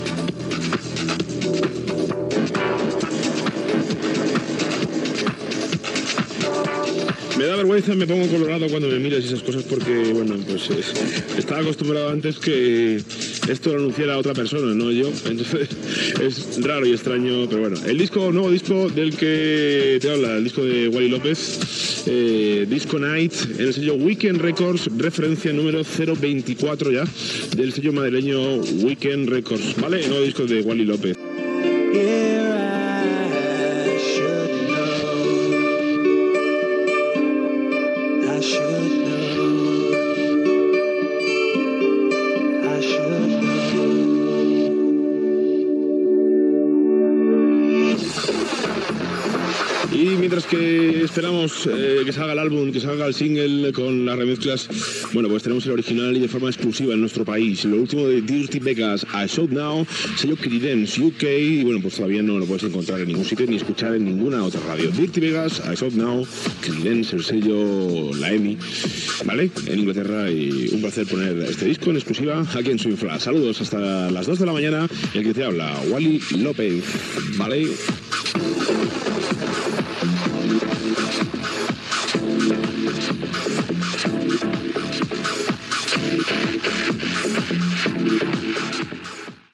Presentació de temes musicals
Musical